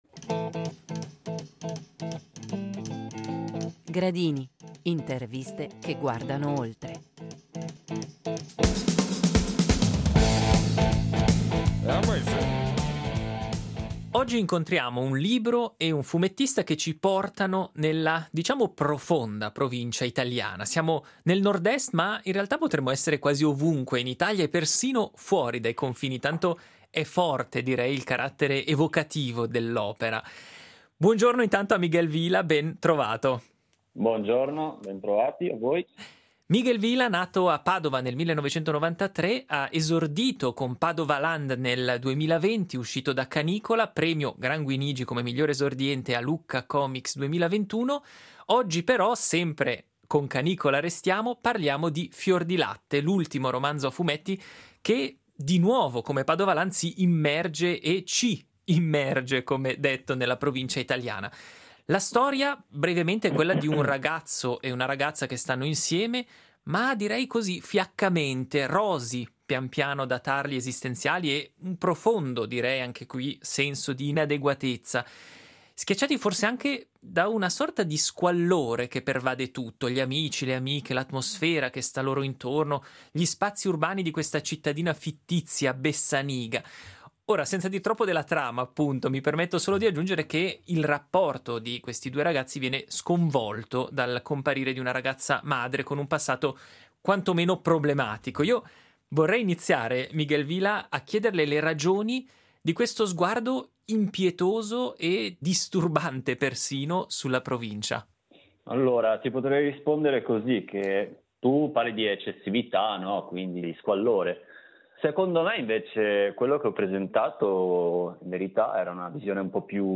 Gradini – Interviste che vanno oltre